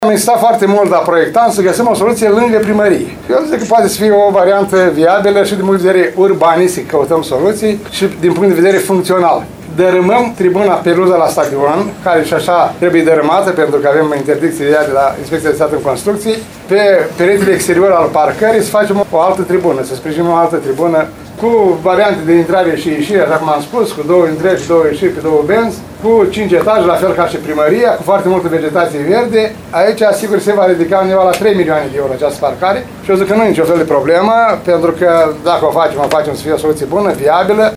Primarul ION LUNGU a declarat astăzi că aceasta este varianta cea mai bună, deoarece construirea unei parcări “la câteva sute de metri distanță nu ar avea eficiență”.